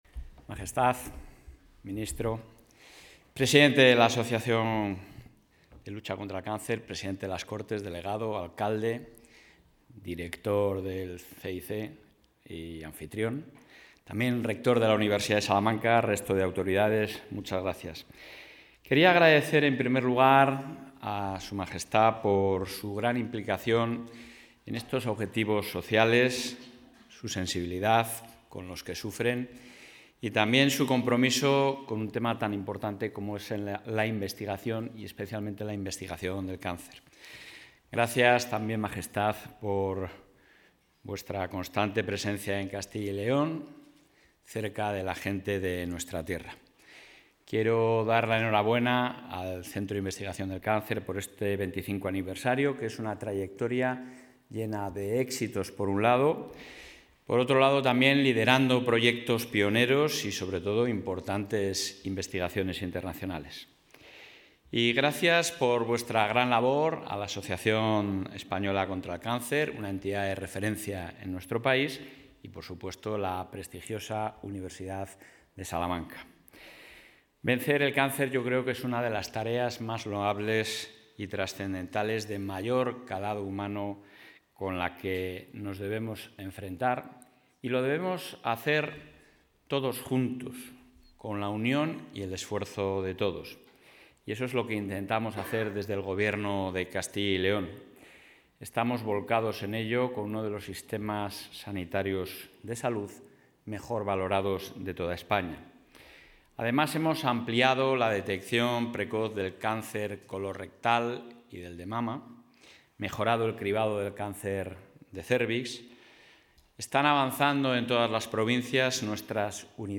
El presidente de la Junta de Castilla y León, Alfonso Fernández Mañueco, ha participado hoy en Salamanca en los actos...
Intervención del presidente de la Junta.